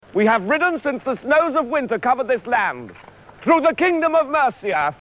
Category: Movies   Right: Personal
Tags: King Arthur Monty Python sounds Holy Grail audio clips Graham Chapman Funny Movie quotes